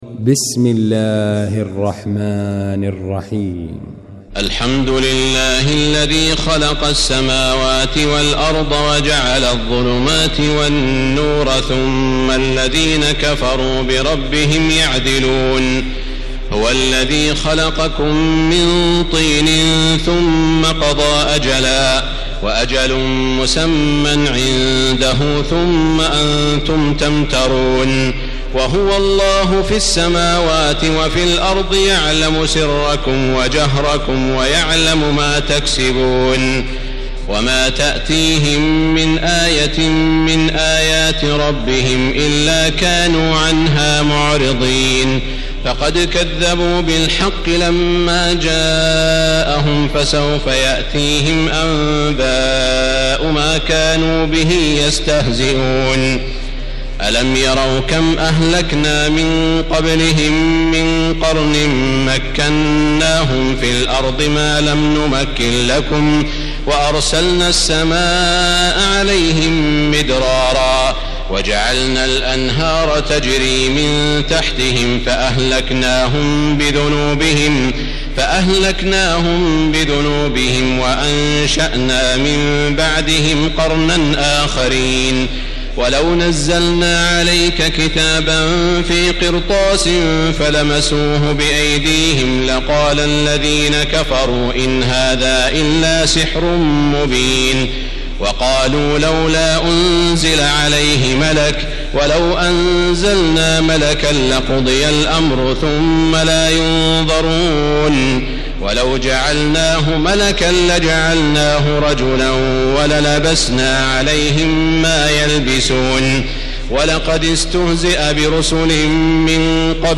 المكان: المسجد الحرام الشيخ: سعود الشريم سعود الشريم معالي الشيخ أ.د. عبدالرحمن بن عبدالعزيز السديس فضيلة الشيخ عبدالله الجهني الأنعام